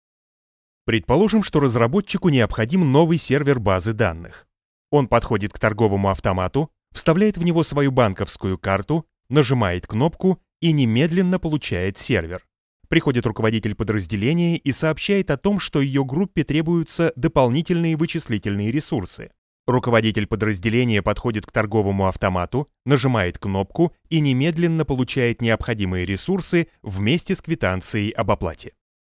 My voice is a clean, accent-free native Russian, adaptable across a wide tonal range – from formal and authoritative to engaging and friendly.
My setup includes an Oktava MKL-5000 tube microphone which gives a rich, warm sound to my voice, ideal for narration, commercials, and corporate reads.
Voice Over Demo Reel
eLearning Training Demo